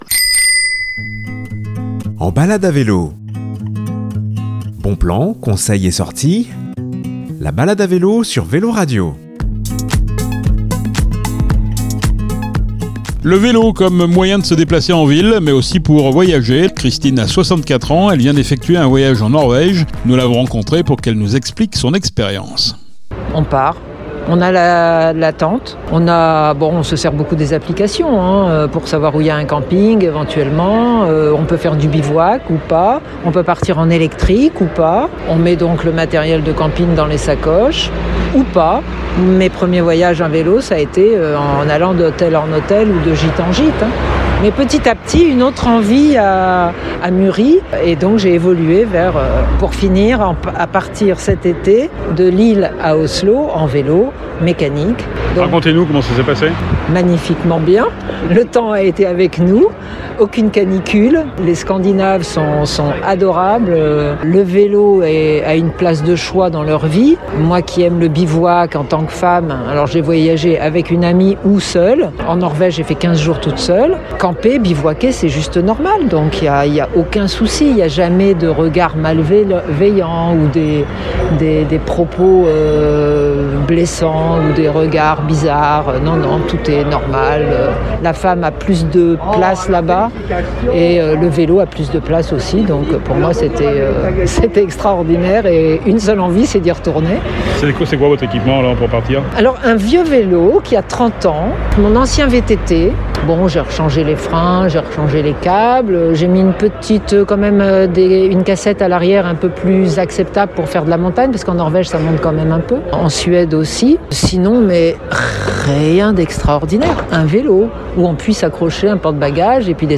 Nous l’avons rencontré pour qu’elle nous explique son expérience…